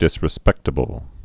(dĭsrĭ-spĕktə-bəl)